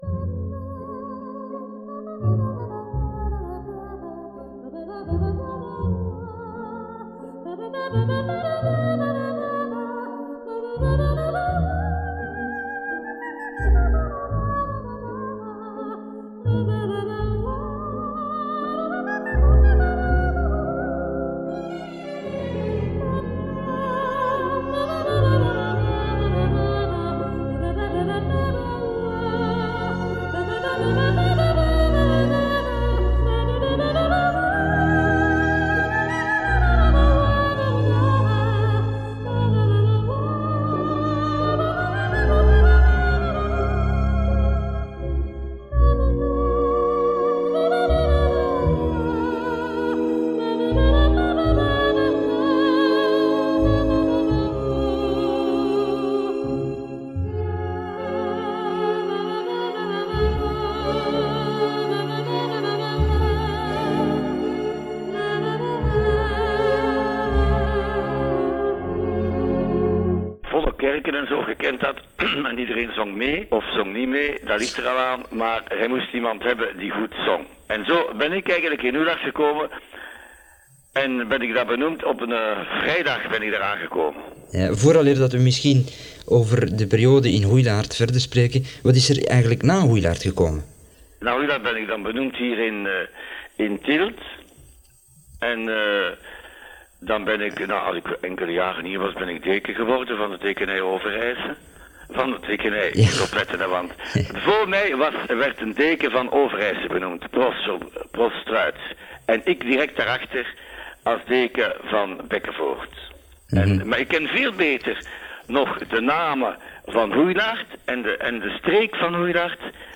Gesprek
Het jaartal is ons onbekend, waarschijnlijk in de nieuwe studio's dus na 1986.